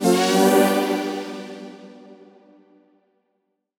FR_ZString[up]-G.wav